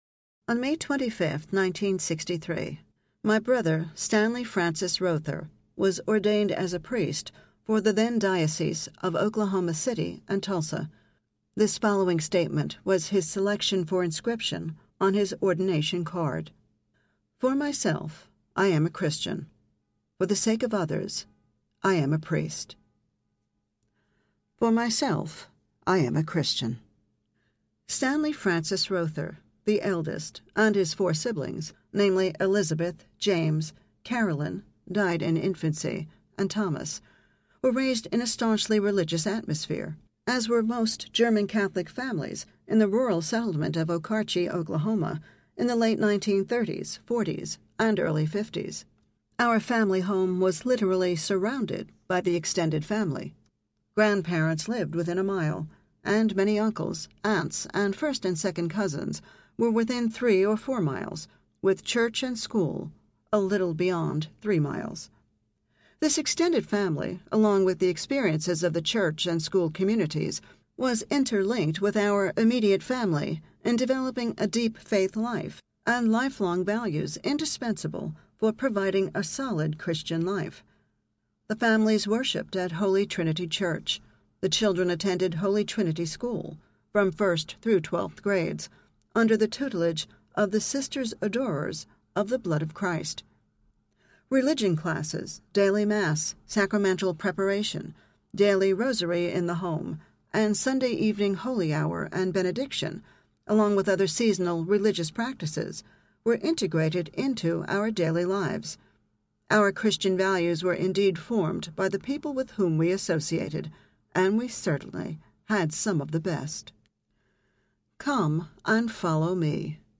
The Shepherd Who Didn’t Run Audiobook
Narrator
6.5 Hrs. – Unabridged